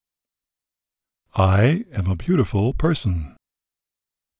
Anyway, I recorded an Affirmation Message.